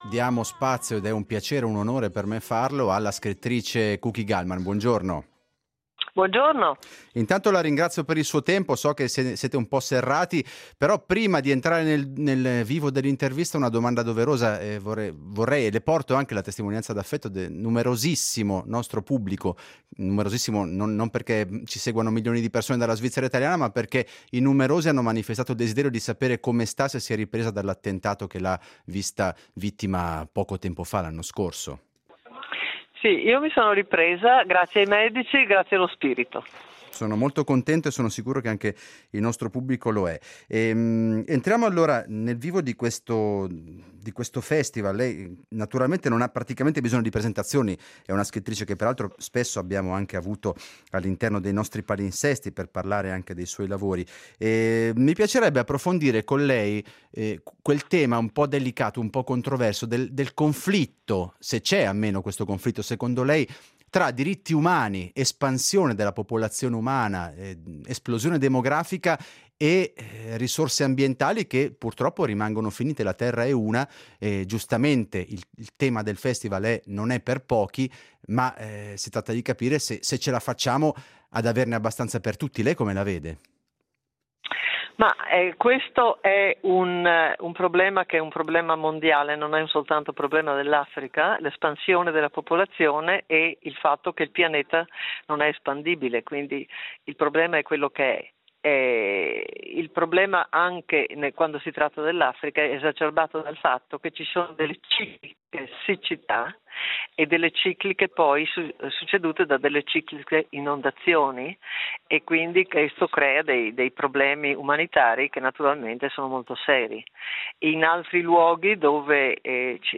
Festival dei diritti umani: incontro con Kuki Gallmann